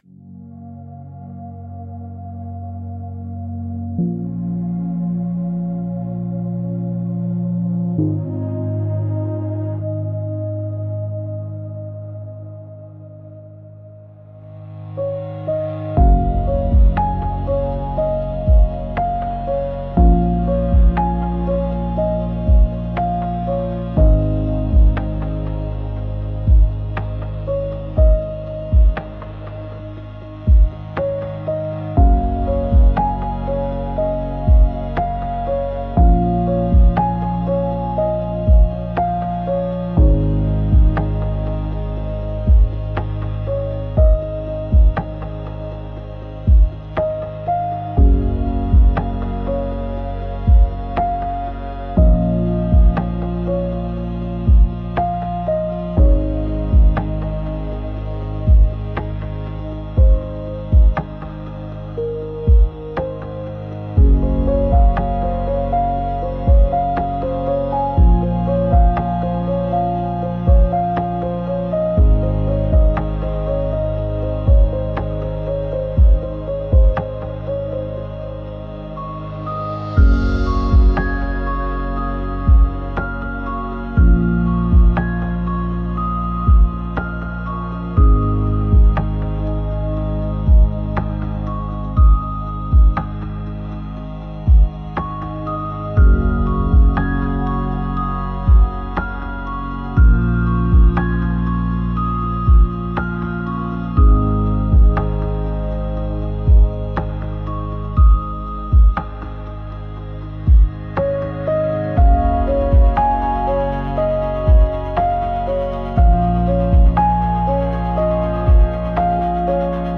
「大人な雰囲気」